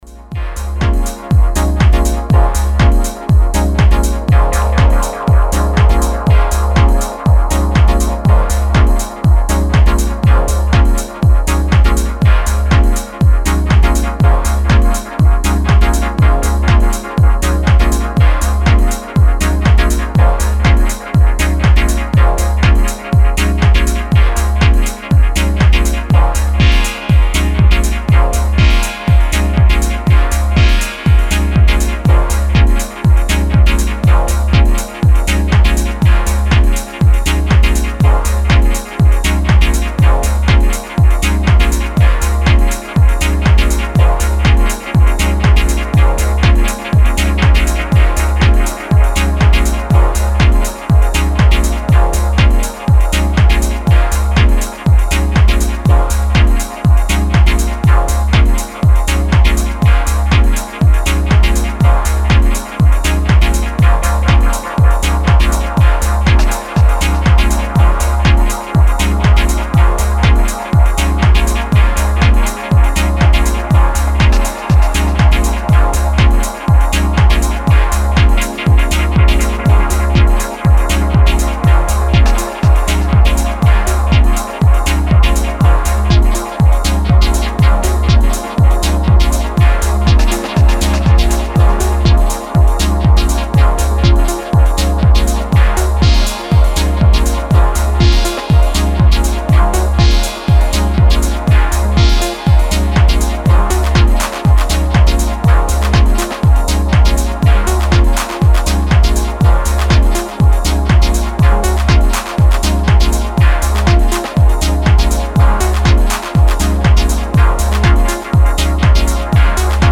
Dub Techno EP
Is this surf techno?
Style: Dub Techno